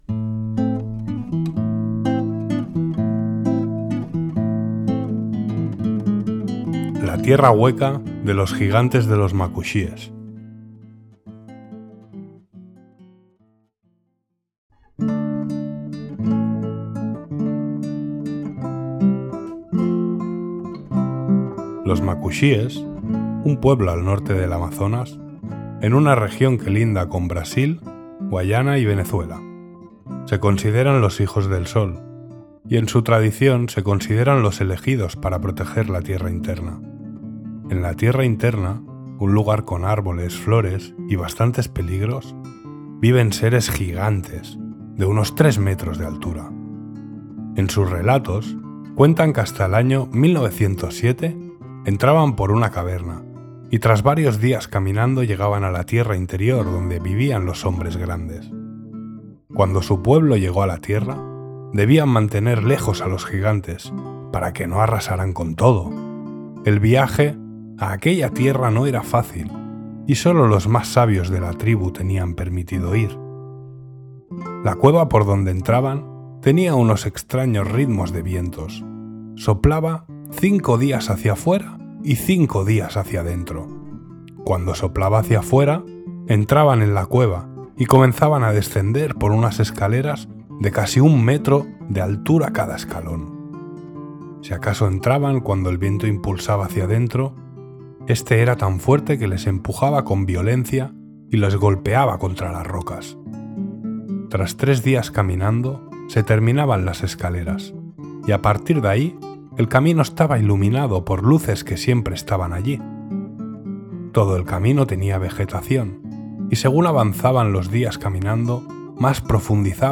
Música interpretada a la guitarra
Folías de España, anónimas